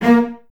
Index of /90_sSampleCDs/Miroslav Vitous - String Ensembles/Cellos/CES Stacc